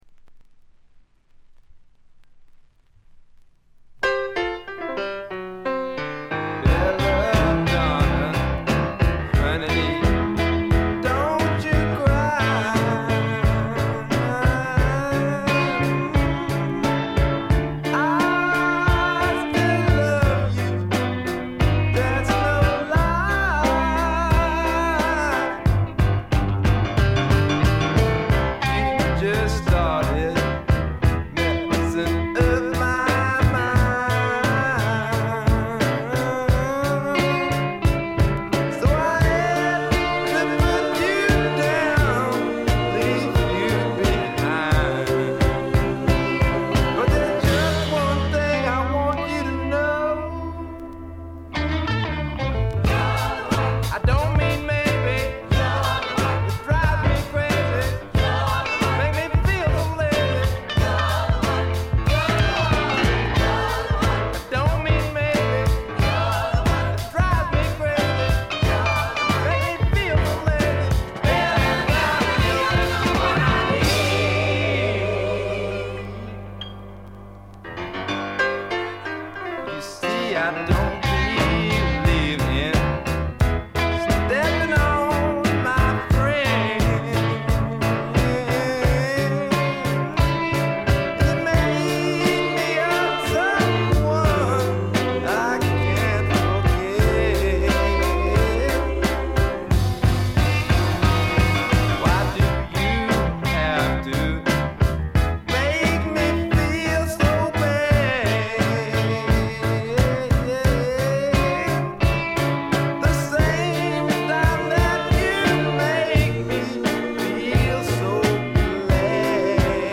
わずかに軽微なチリプチ。
まさしくスワンプロックの真骨頂。
試聴曲は現品からの取り込み音源です。
Vocal, Guitar, Keyboards